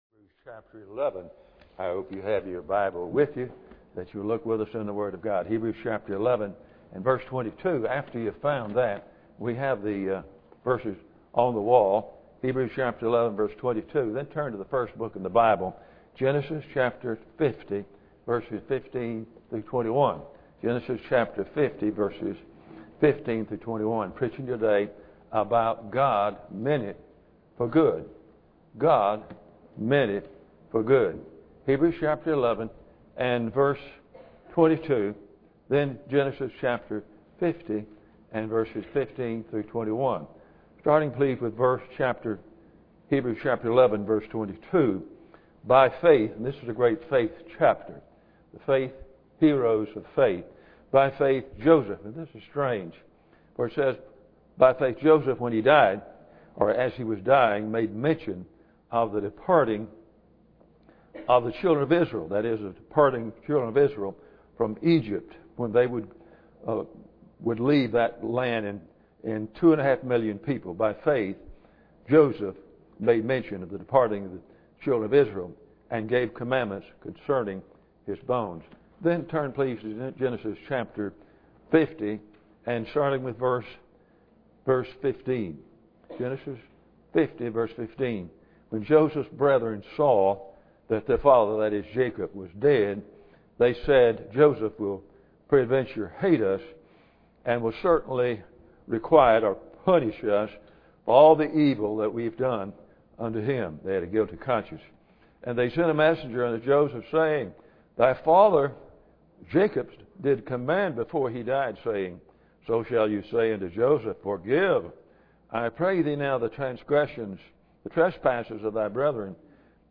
Passage: Hebrews 11:22 Service Type: Sunday Morning